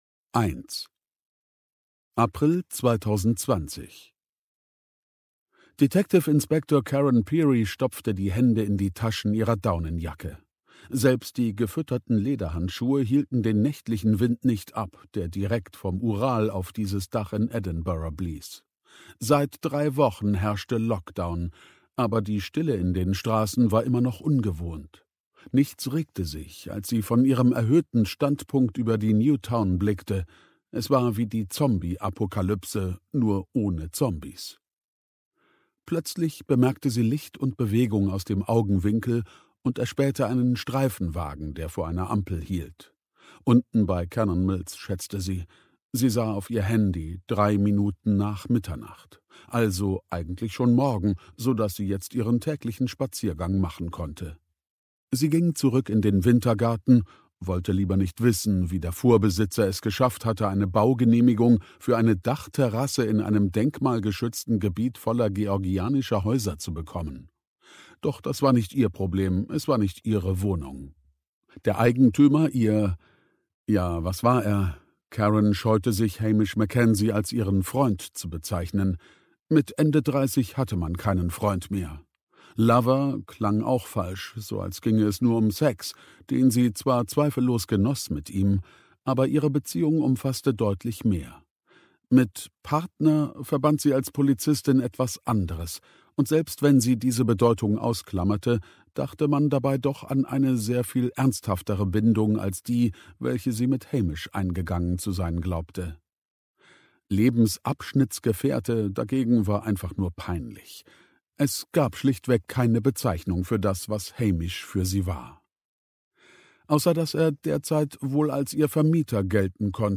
steinbach sprechende bücher | Hörbücher
Produktionsart: ungekürzt